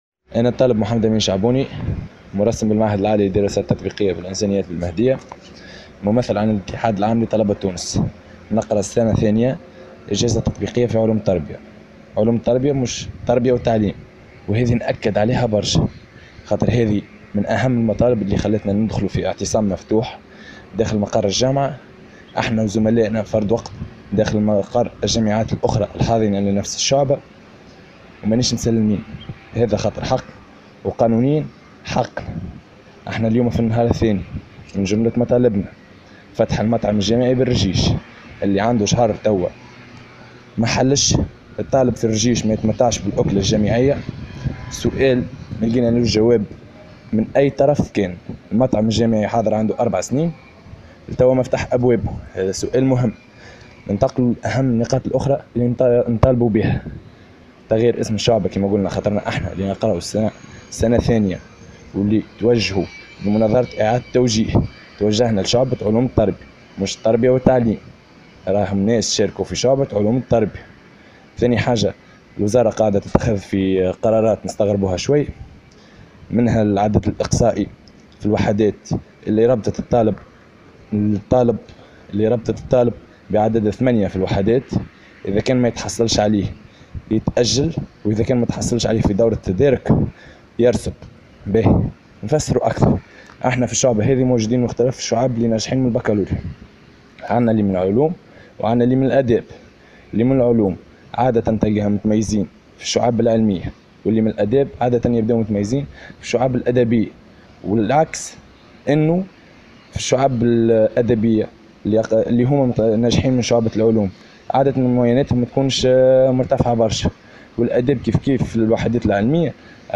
وأكد ممثل عن الطلبة المعتصمين، في تصريح لمراسل الجوهرة أف أم، اليوم الخميس، أن من بين المطالب إلغاء أحد الشروط التي وضعتها الوزارة لارتقاء الطلبة وهي الحصول على عدد 8 من 20 على الأقل في كل مادة.